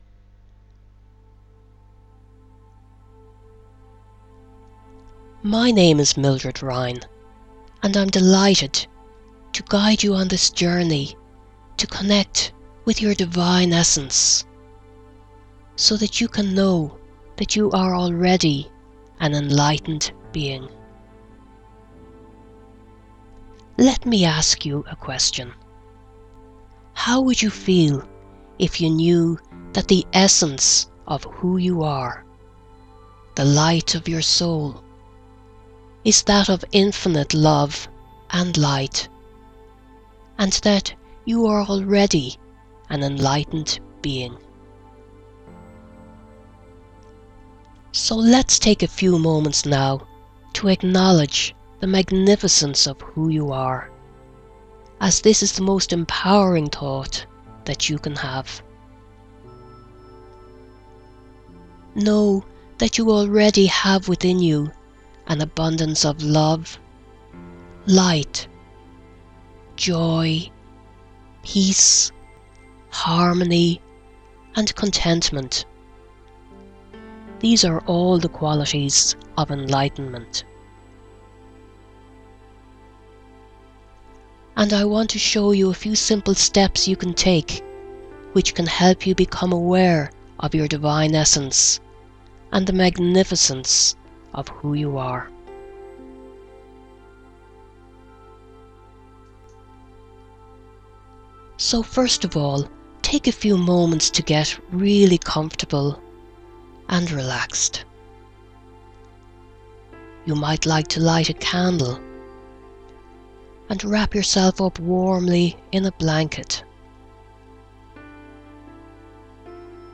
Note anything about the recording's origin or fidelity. It is helpful to be guided through the whole process, so I have recorded the steps for you in a guided meditation.